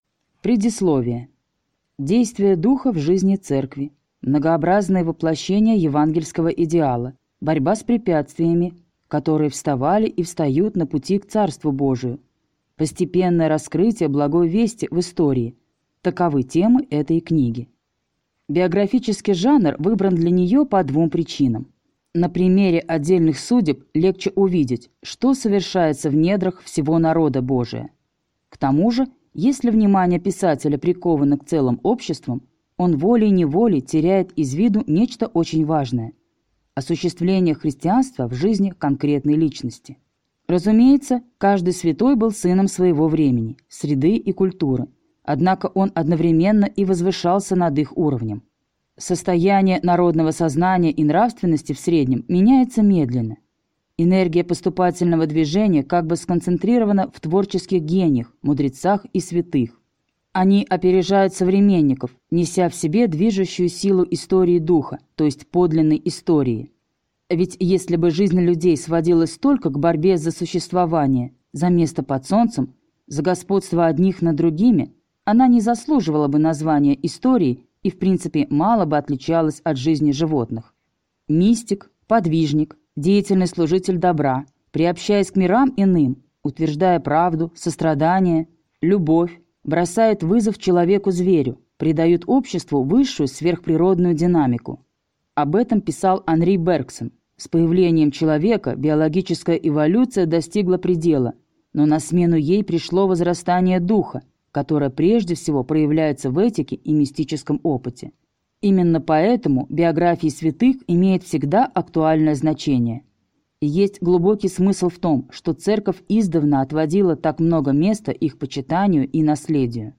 Аудиокнига Первые апостолы | Библиотека аудиокниг